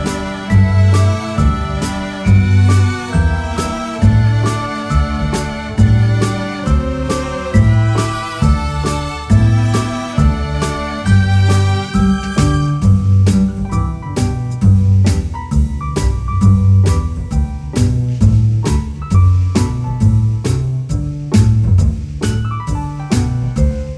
no Backing Vocals Crooners 2:14 Buy £1.50